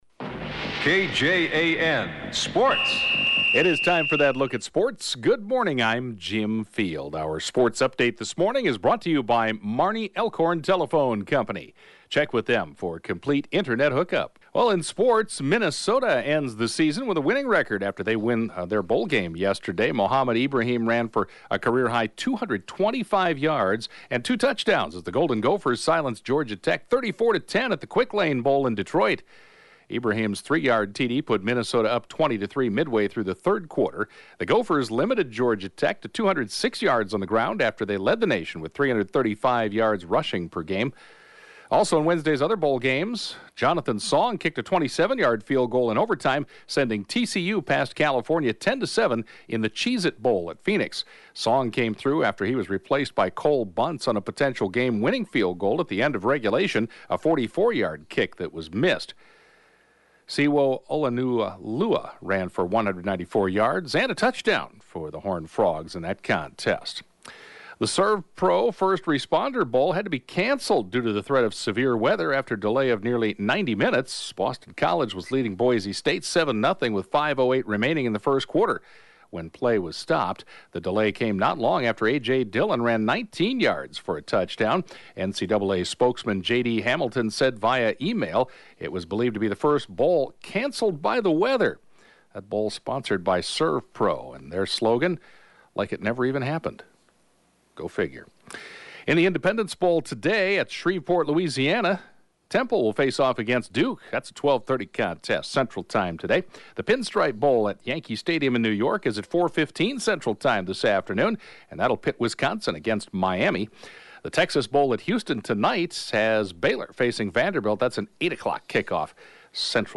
The 7:20-a.m. Sportscast